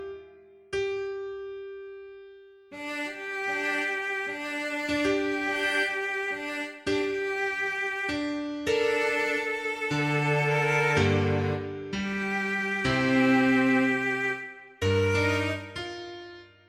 Продукт уже можно генерировать, но сейчас его качество сложно назвать приемлемым, сеть показывает лишь базовое понимание гармонических сочетаний и ритма.